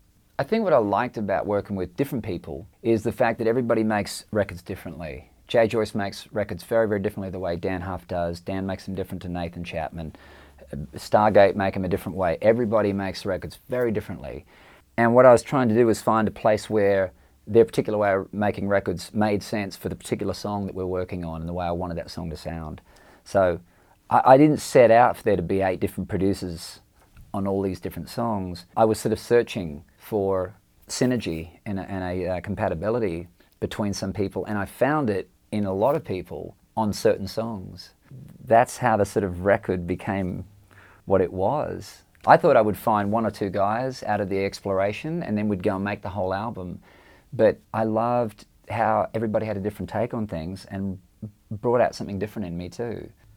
AUDIO: Keith Urban talks about working with all of the different producers on his new project, Fuse.